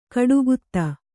♪ kaḍugutta